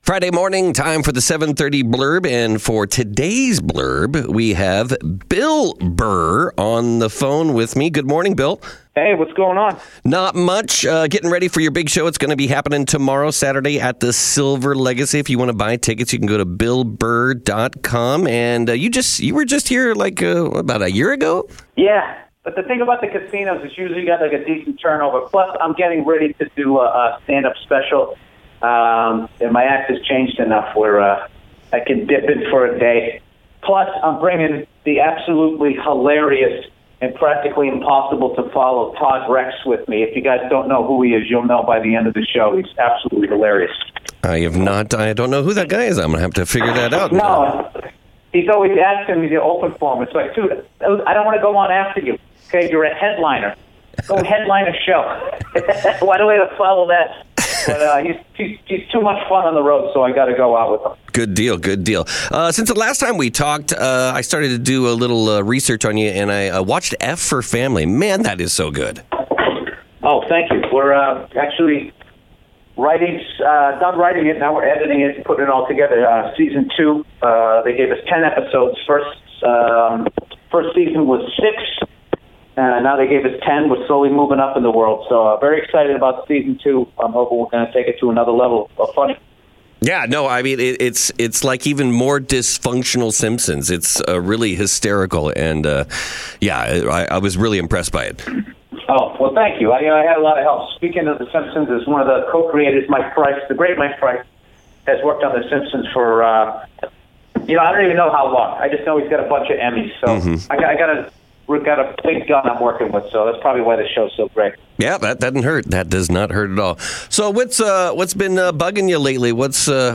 Bill Burr Interview